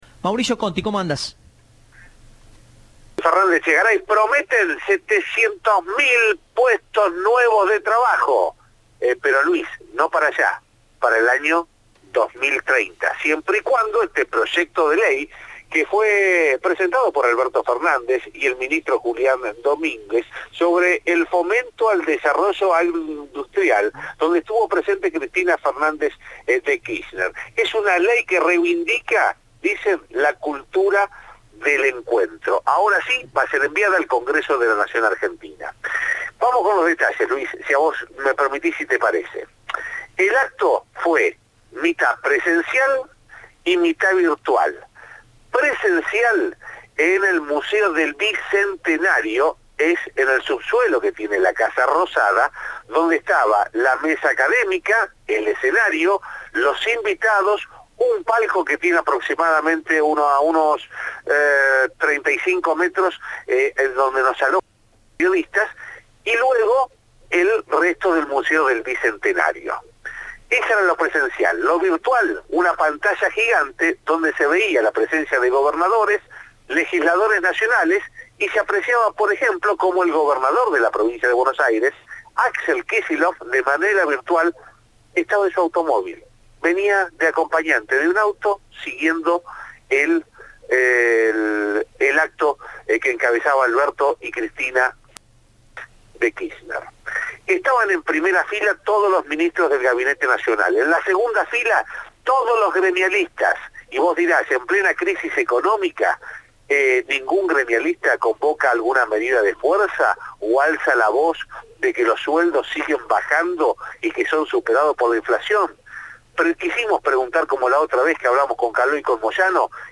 Fue en un acto en la Casa Rosada tras varios días de bajo perfil del jefe de Estado.
"Esta ley es el resultado de escucharnos, buscar puntos de encuentro y ver cómo conciliamos intereses que no siempre son necesariamente los mismos", sostuvo Fernández durante la presentación realizada en la Casa Rosada con la presencia de funcionarios y representantes del sector agropecuario.